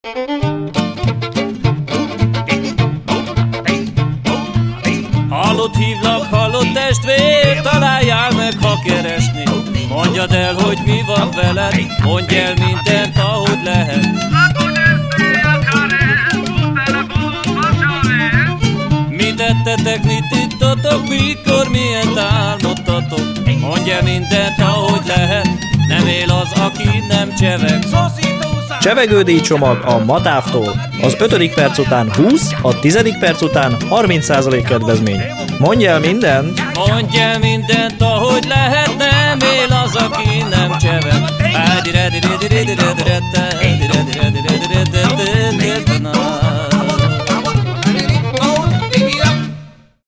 MATAV_reklam_ciganyoknak_a_Radio_C-n.mp3